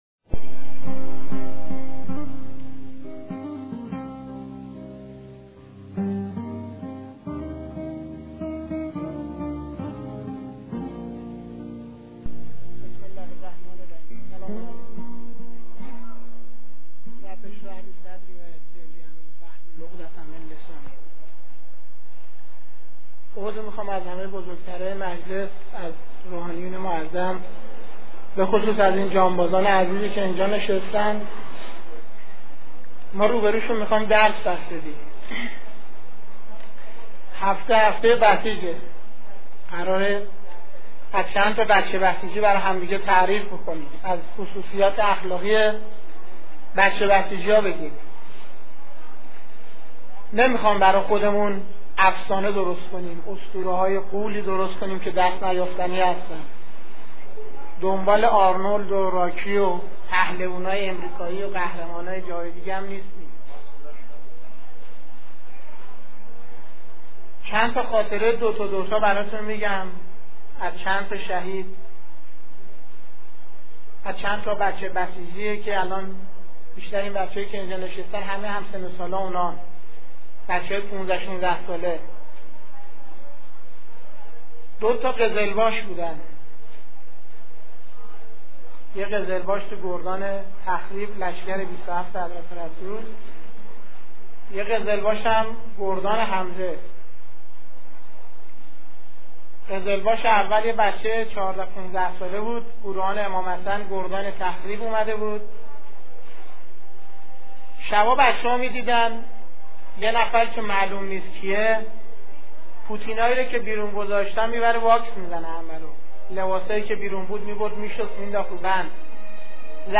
صوت روایتگری
ravayatgari101.mp3